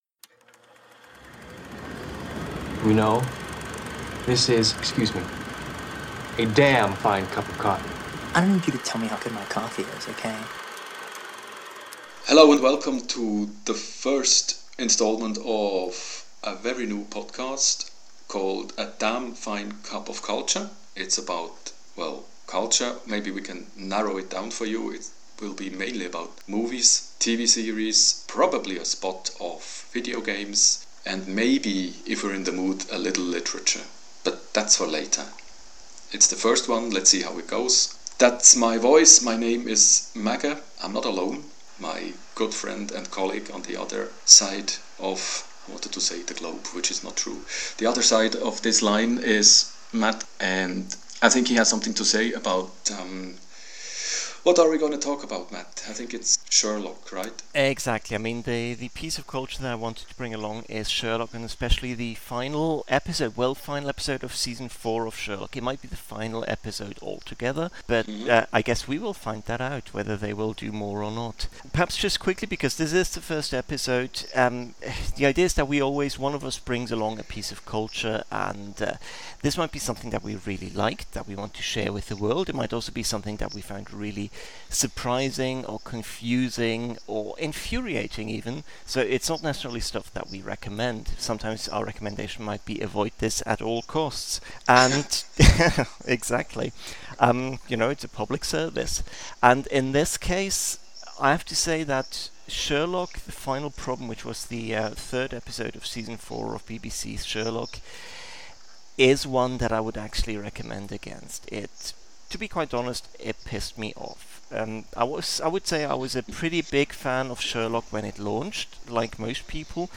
The sound quality isn’t at its best, but the energy is there, and we had a lot of fun talking about the BBC’s Sherlock – so we’re sharing it with you.